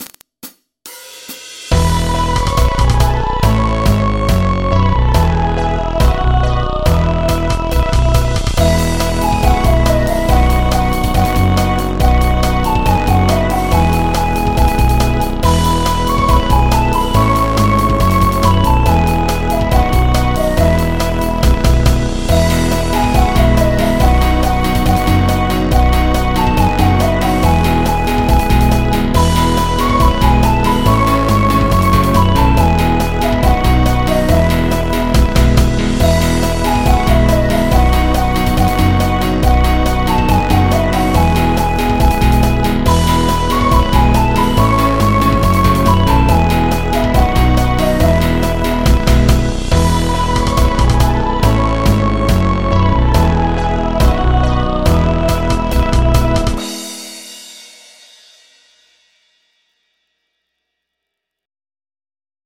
MIDI 15.39 KB MP3